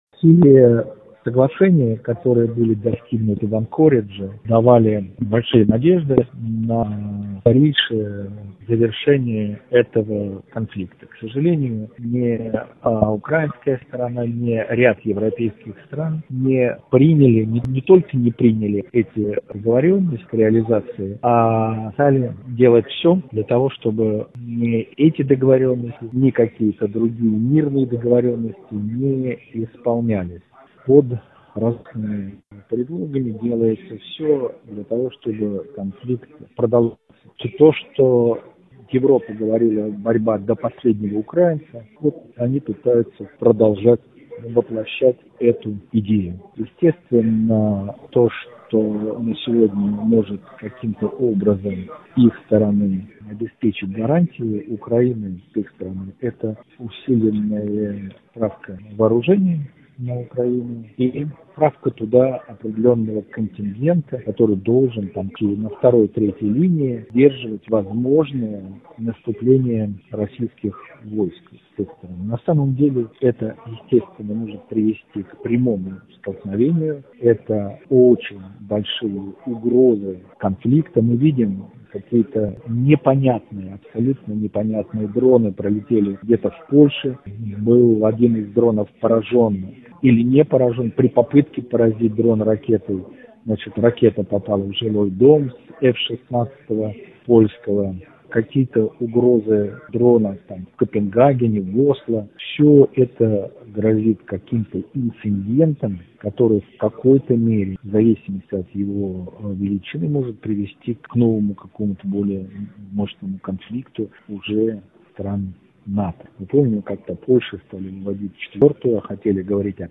ГЛАВНАЯ > Актуальное интервью
Первый заместитель председателя комитета Госдумы по международным делам Алексей Чепа в интервью журналу «Международная жизнь» рассказал о ситуации, связанной с заключением мира между Россией и Украиной: